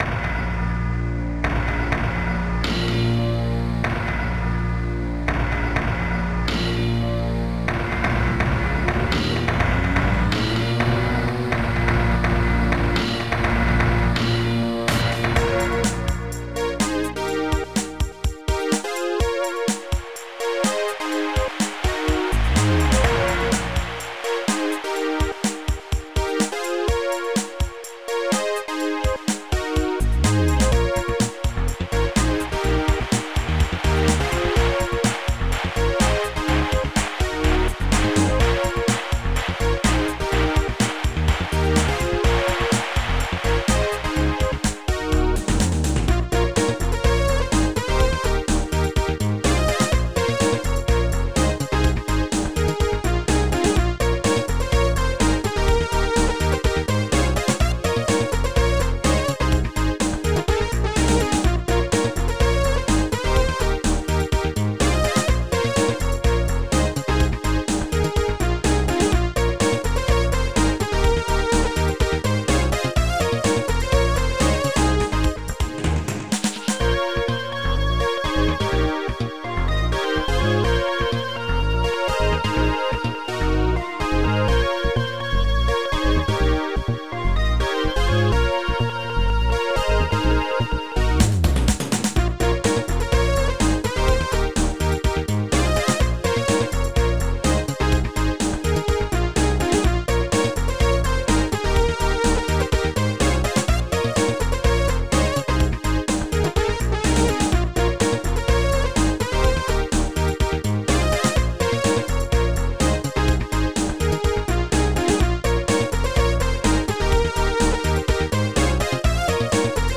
Protracker and family
music has 3 parts...
reggea-party..samples
from korg-x3
metalsynth from
4 channel compo.....